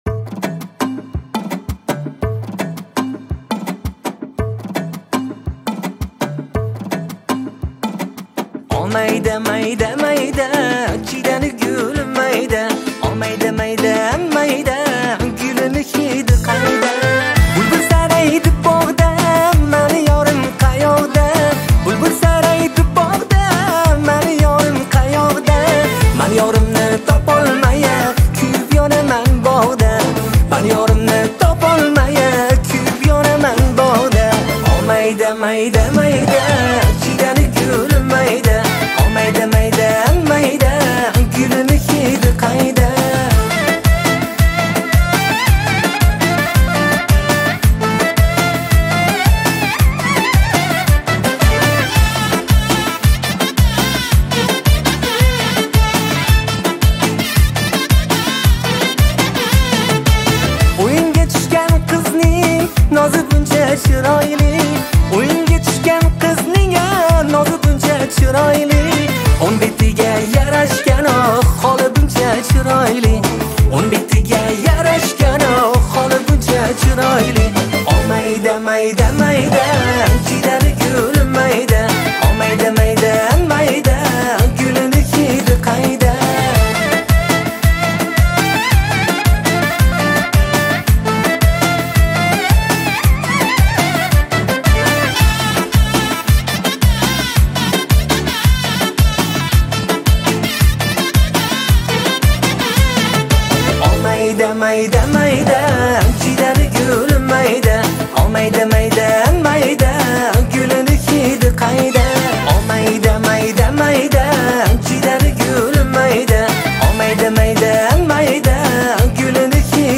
Категория: Узбекские